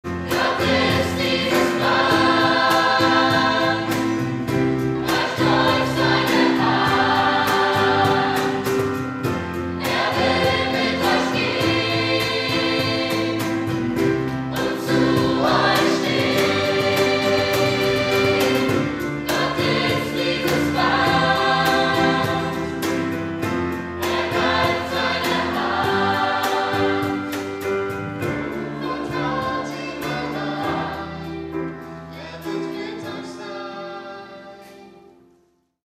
Notation: SATB
Tonart: C
Taktart: 4/4
Tempo: 98 bpm
Parts: 3 Verse, Refrain
Noten, Noten (Chorsatz)